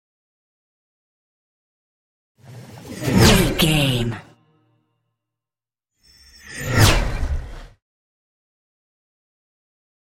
Scifi passby whoosh fast
Sound Effects
Atonal
Fast
futuristic
intense
whoosh